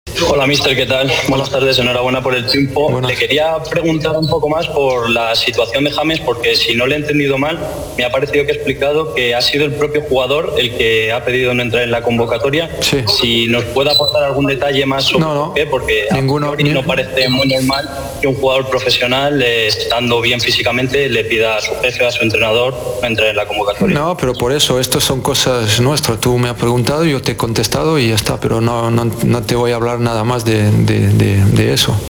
(Zinedine Zidane, técnico del Real Madrid)